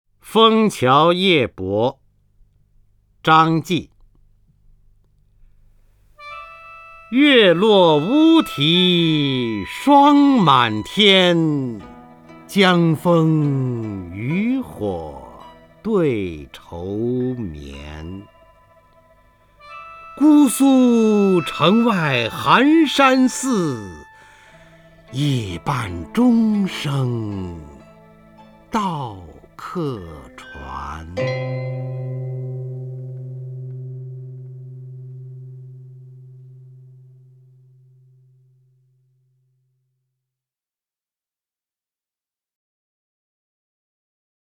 方明朗诵：《枫桥夜泊》(（唐）张继) （唐）张继 名家朗诵欣赏方明 语文PLUS
（唐）张继 文选 （唐）张继： 方明朗诵：《枫桥夜泊》(（唐）张继) / 名家朗诵欣赏 方明